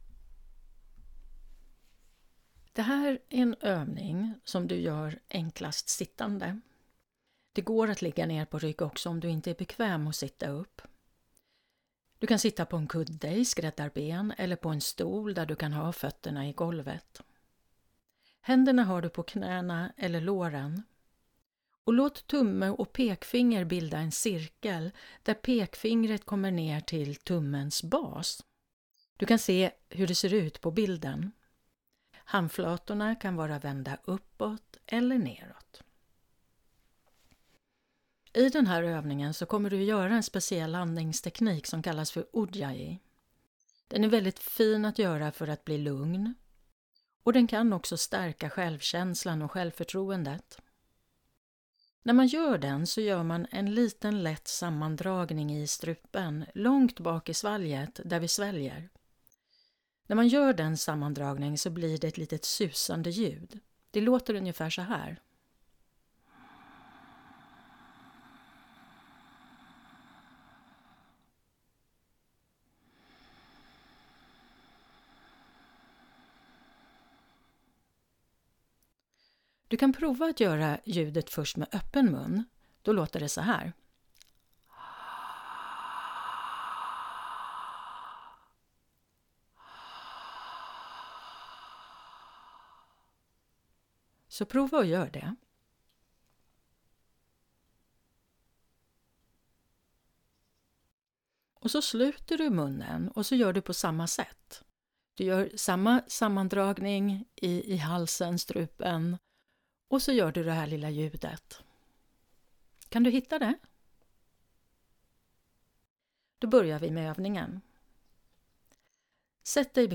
Ujjayi – andningsövning